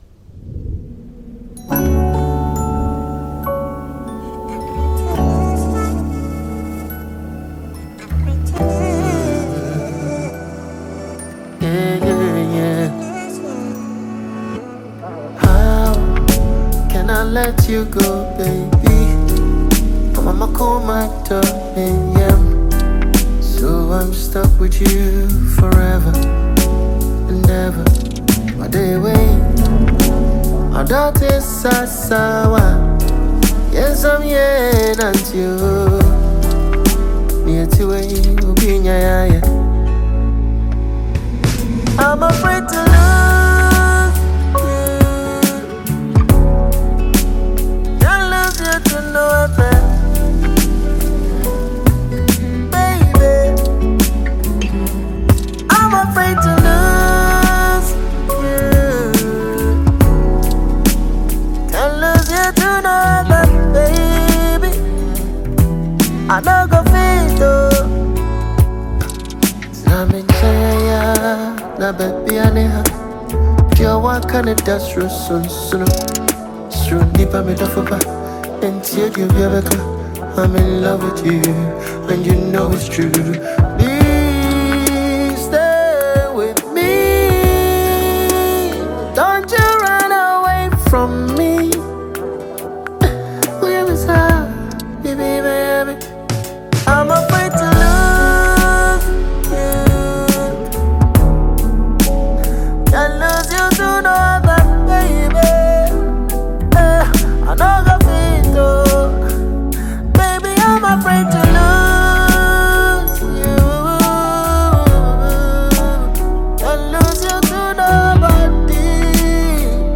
highlife
love song